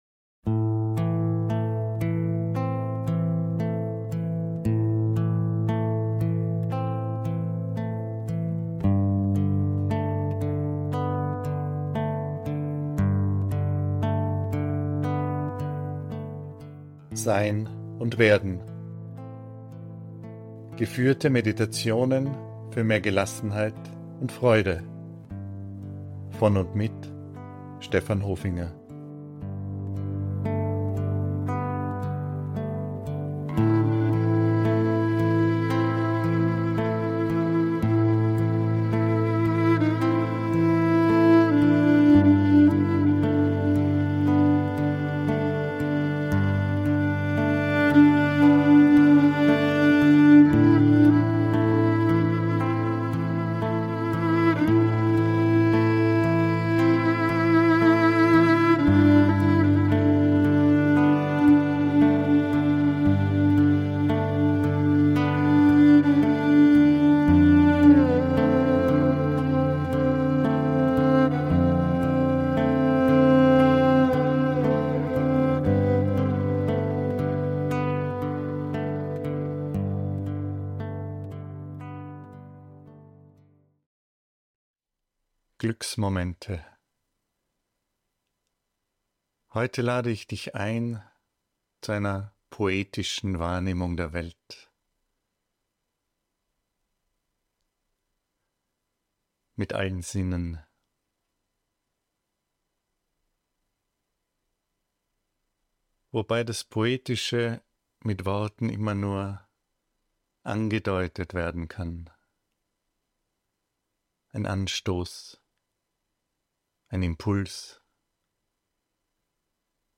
Heute lade ich ein zu einer poetischen Wahrnehmung der Welt - mit allen Sinnen. Die Meditation möchte eine empfindsame Offenheit wecken für die kleinen Glücksmomente des Alltags.